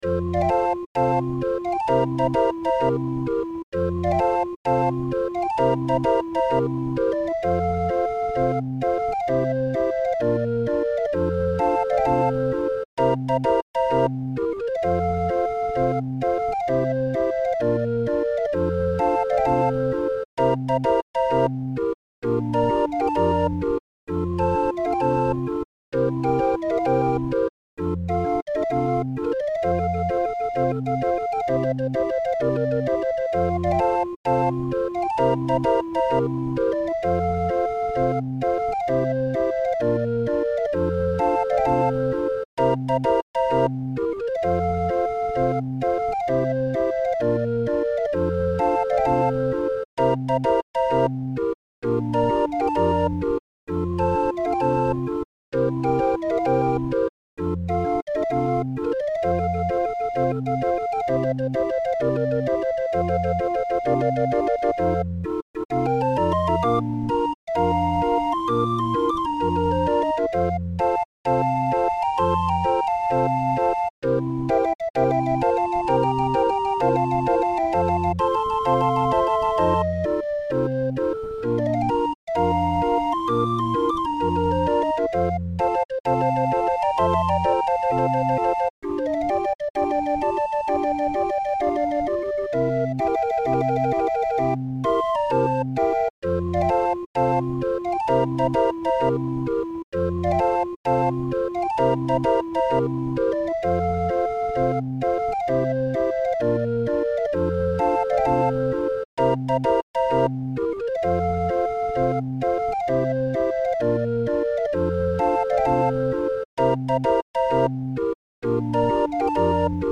Musikrolle 20-er